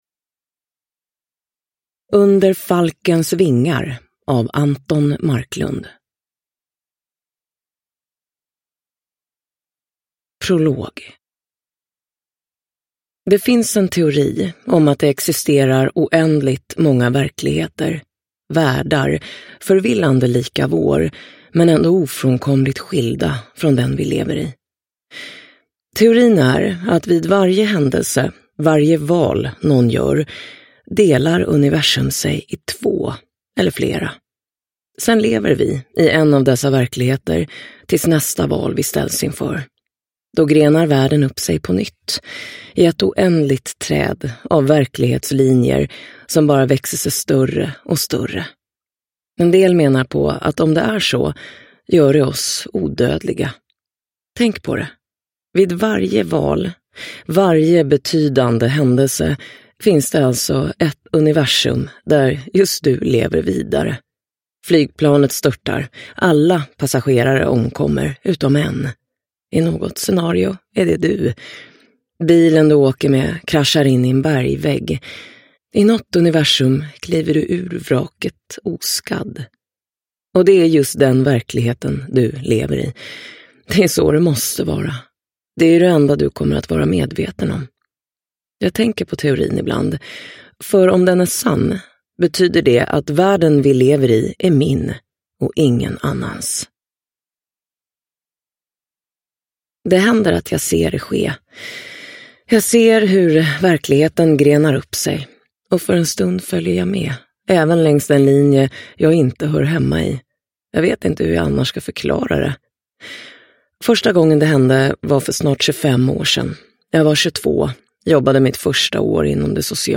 Under falkens vingar – Ljudbok – Laddas ner